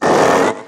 ursaluna_ambient.ogg